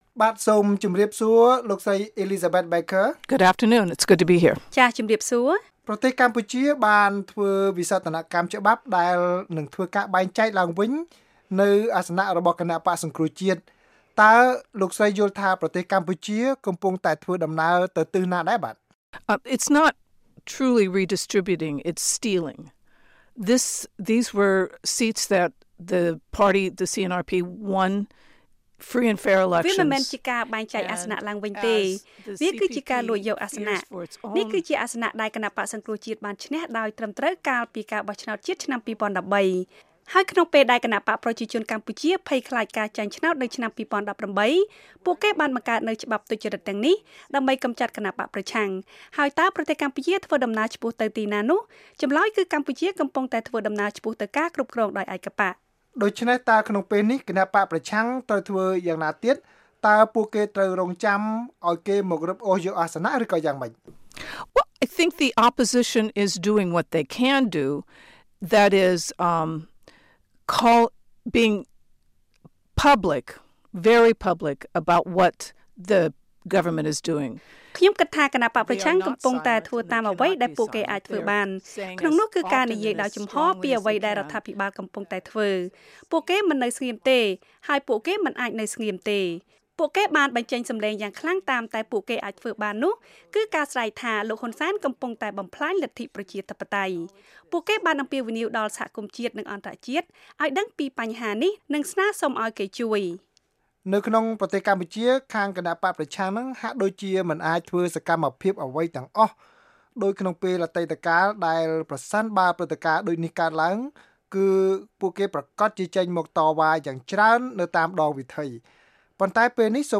បទសម្ភាសន៍
បទសម្ភាសន៍ VOA៖ អ្នកជំនាញថាបក្សកាន់អំណាចភិតភ័យខ្លាចចាញ់ឆ្នោតទើបកែច្បាប់និងចោទបក្សប្រឆាំង